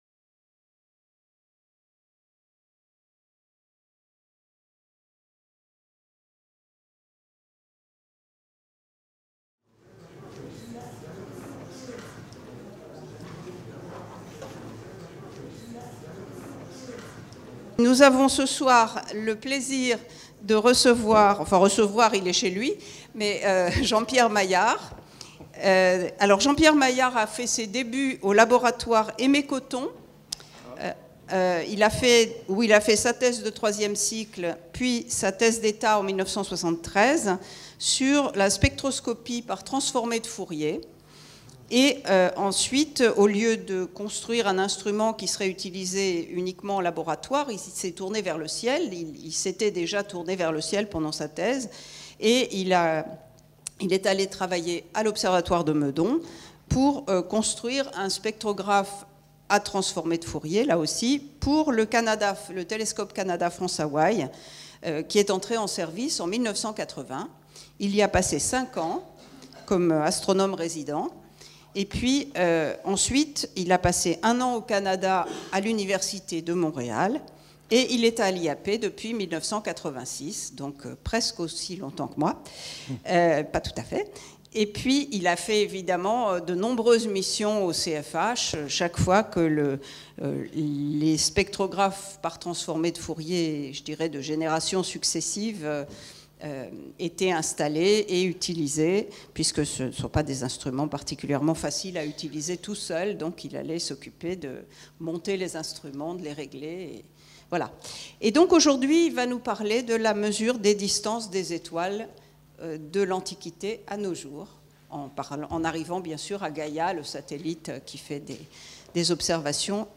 Le sous-titre de la conférence proposée pourrait être : histoire de la découverte de notre place dans l'Univers.